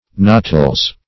Search Result for " natals" : The Collaborative International Dictionary of English v.0.48: Natals \Na"tals\, n. pl. One's birth, or the circumstances attending it.
natals.mp3